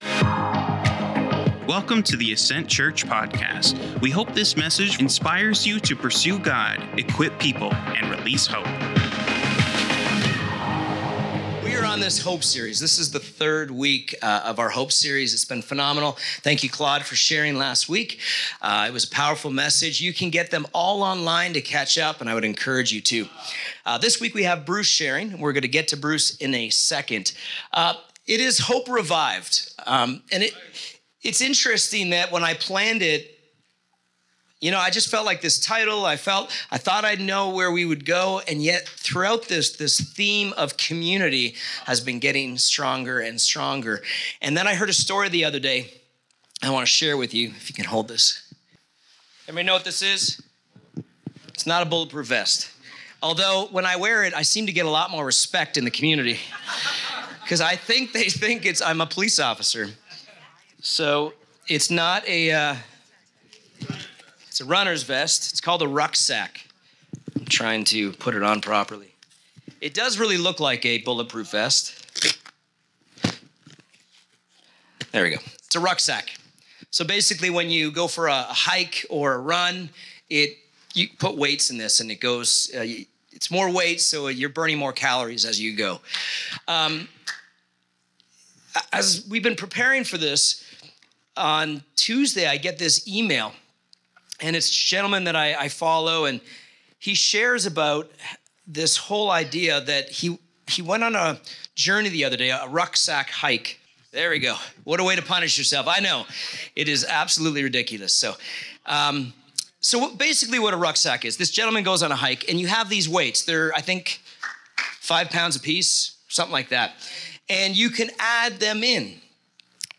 Sermons | Ascent Church